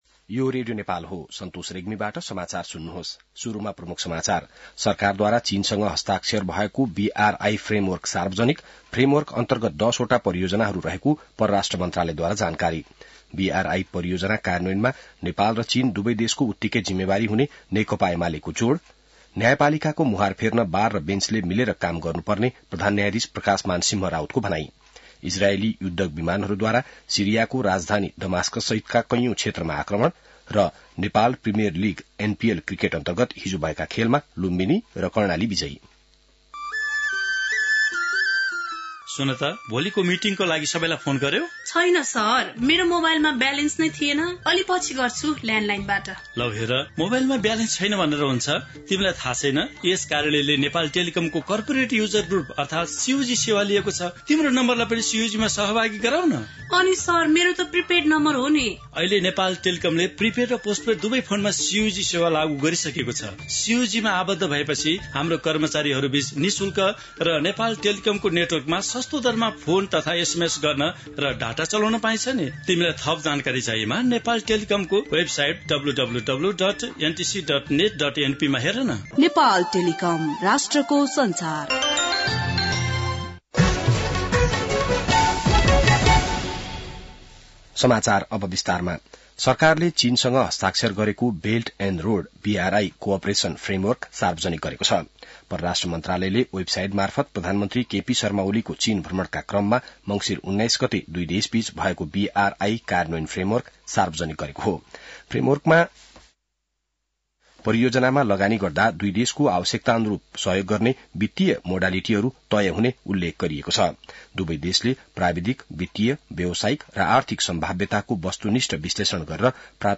बिहान ७ बजेको नेपाली समाचार : २७ मंसिर , २०८१